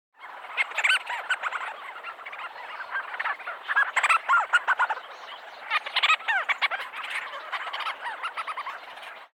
コシジロウミツバメ｜日本の鳥百科｜サントリーの愛鳥活動